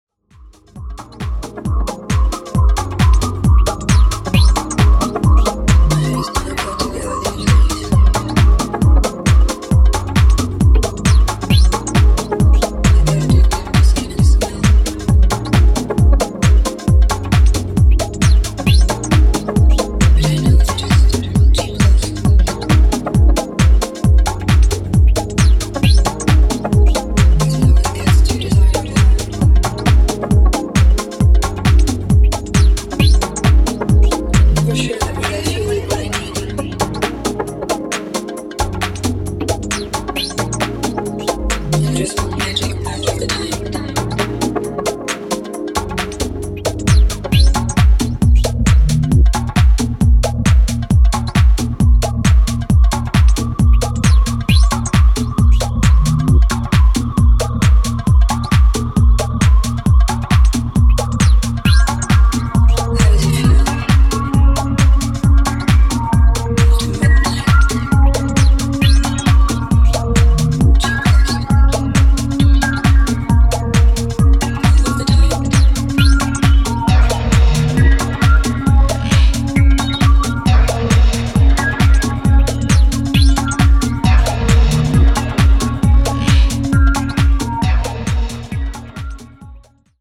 軽快な足回りのテック・ハウス4曲を収録
BPM135で滑らかなコードを走らせた